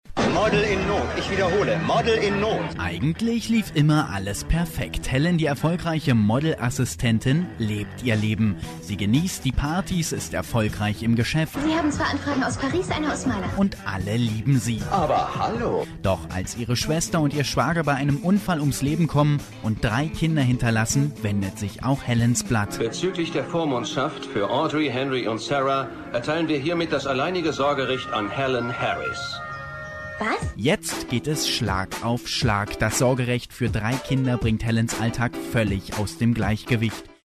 deutscher Profi - Sprecher für Firmenpräsentationen, Hörbuchproduktionen, Radio
Kein Dialekt
Sprechprobe: Werbung (Muttersprache):
german voice over talent